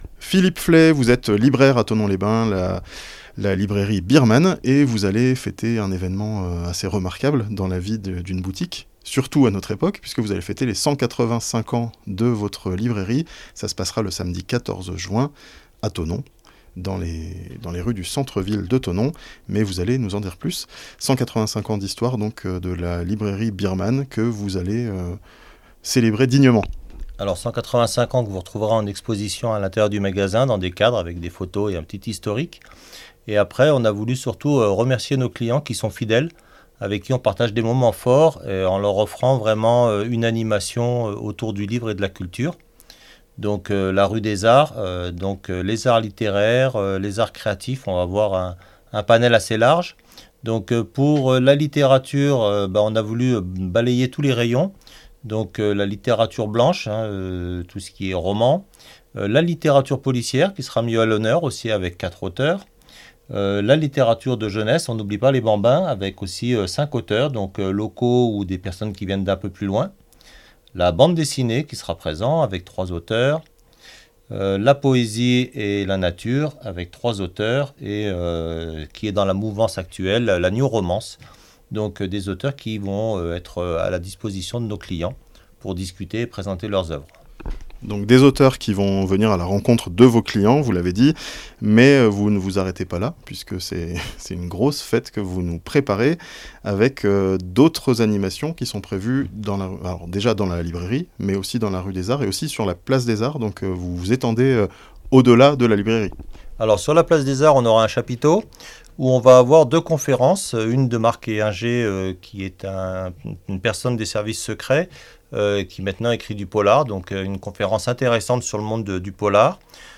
A Thonon, la plus ancienne librairie de Haute-Savoie va fêter ses 185 ans (interview)